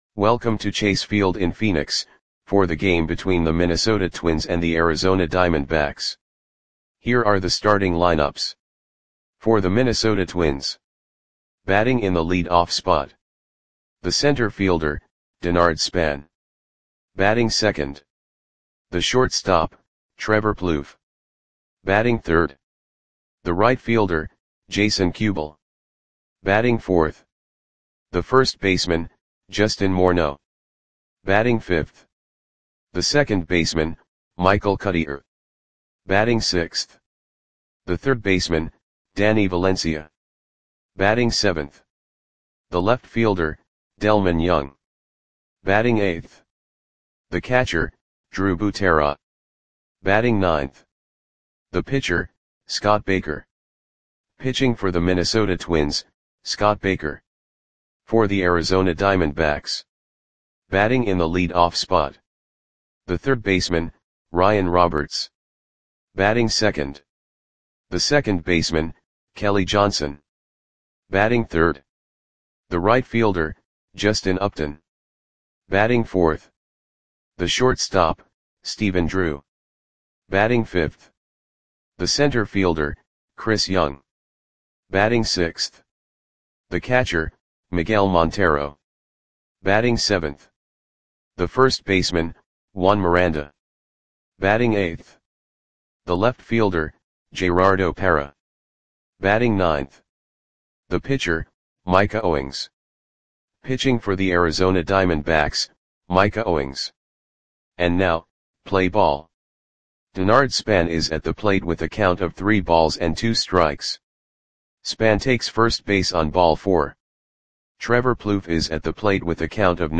Audio Play-by-Play for Arizona Diamondbacks on May 21, 2011
Click the button below to listen to the audio play-by-play.